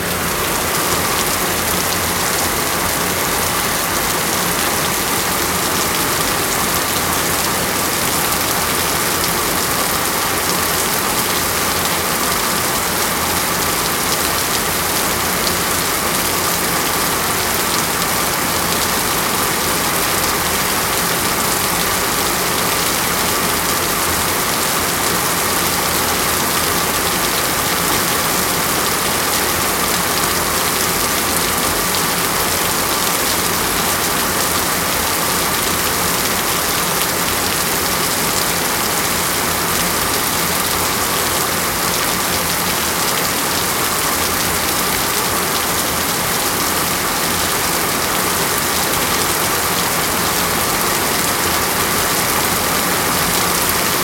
mandrake foundry13data/Data/modules/soundfxlibrary/Nature/Loops/Rain
rain-3.mp3